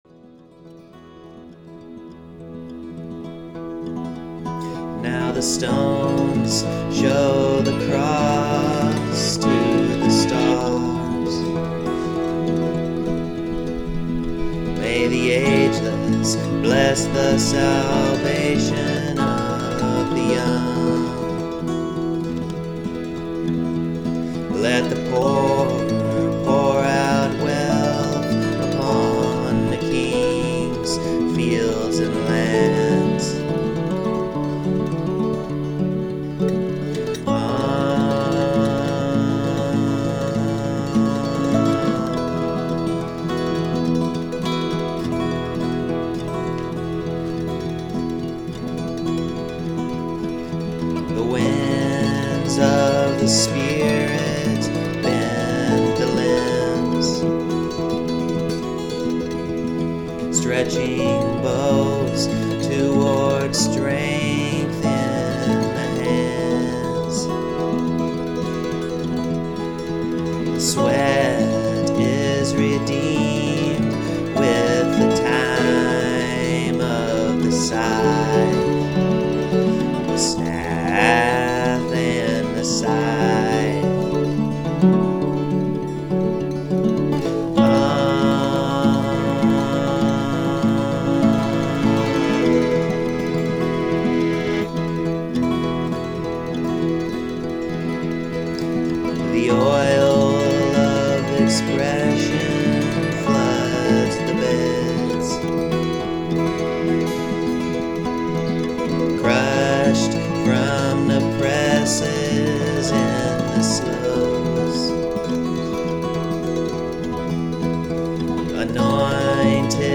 The recordings are all quite amateur, and most are, what I would refer to as, sketches, as opposed to finished and refined pieces of music.
Most are instrumental guitar, some are banjo and a few other instruments that I play with enough skill to record with them.